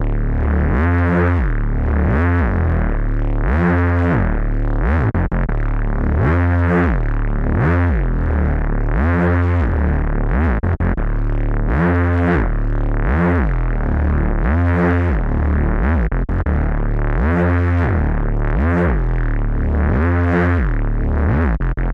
Tag: 75 bpm Psychedelic Loops Guitar Electric Loops 4.31 MB wav Key : Unknown